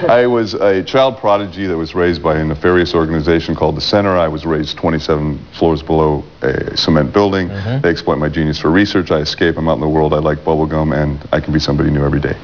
Arquivos de sons da entrevista: